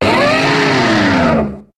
Cri de Mammochon dans Pokémon HOME.